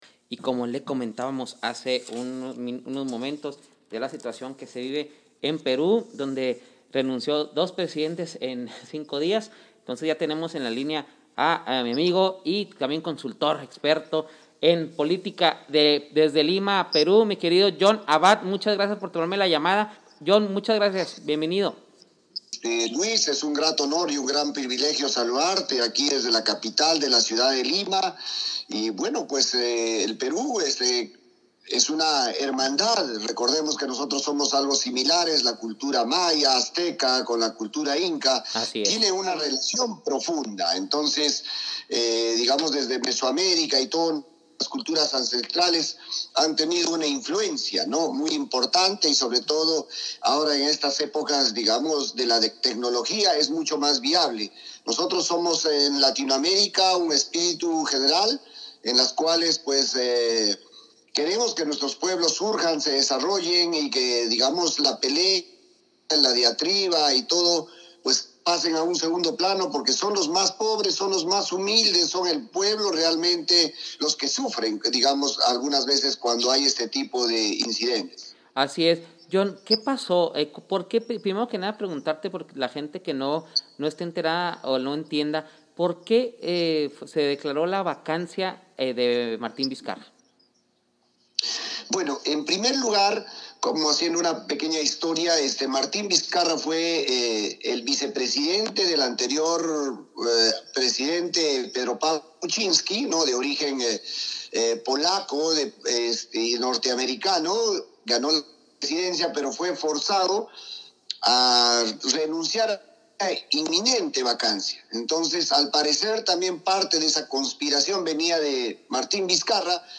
estuvo en entrevista vía telefónica desde Lima, Perú